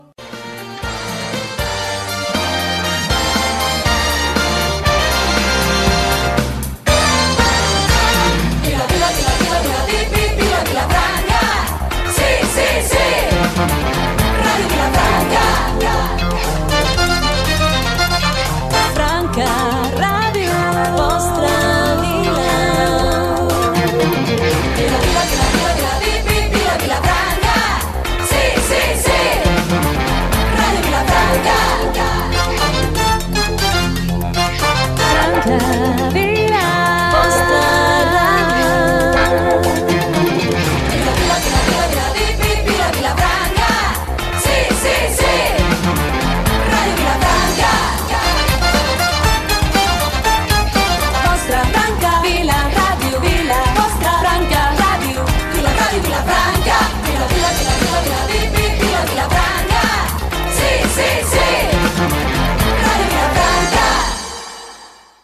Versió de la priemra sintonia cantada de la ràdio